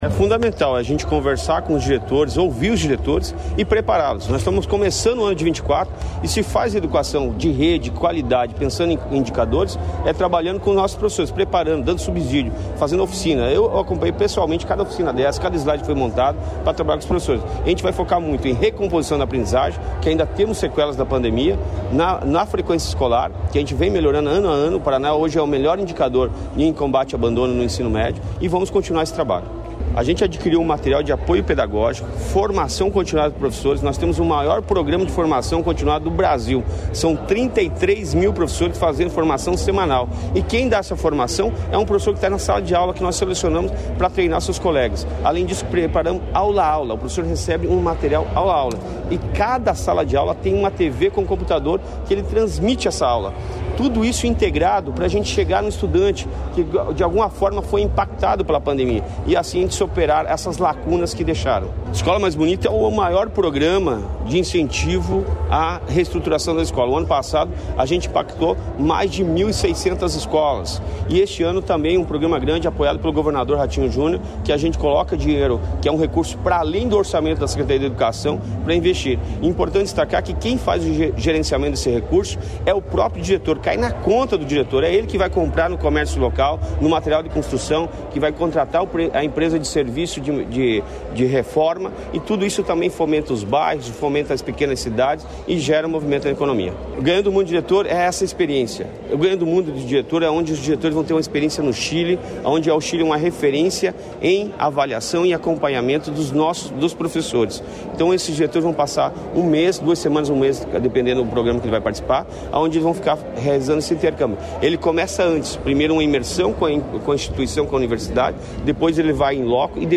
Sonora do secretário da Educação, Roni Miranda, sobre os anúncios feitos para a área no Seminário dos Diretores com Foco na Aprendizagem
RONI MIRANDA - SEMINÁRIO FOZ DO IGUAÇU.mp3